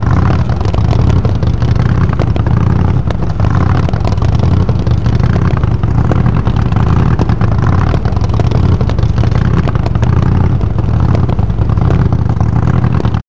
Motor Idling Sound
transport
Motor Idling